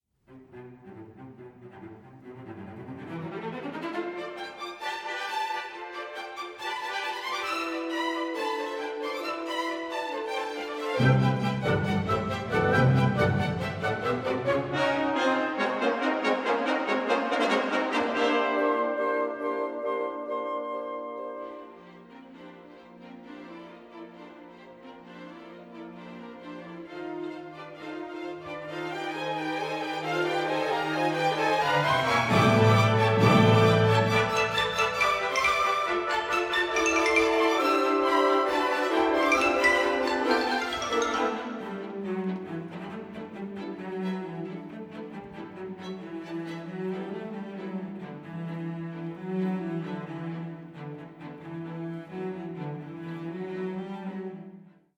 for symphony orchestra - Molto vivace